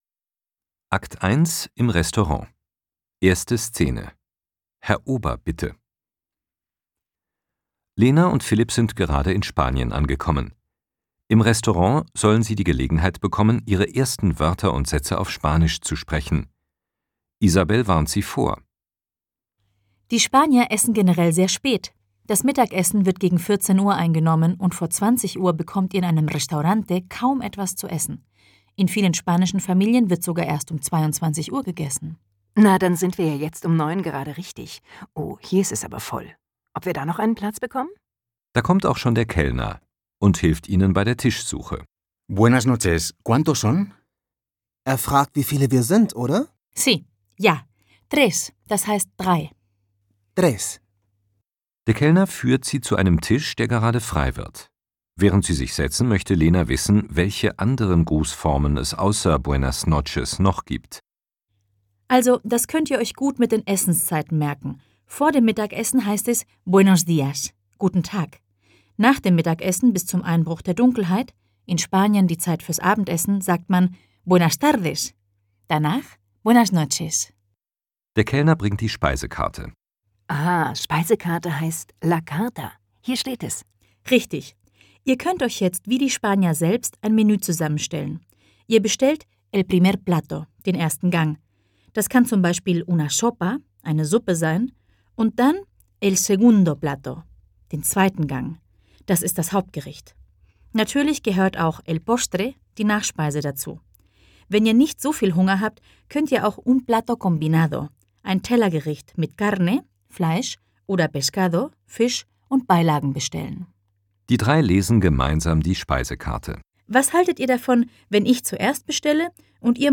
Flexibel: Audiotraining für zu Hause und unterwegs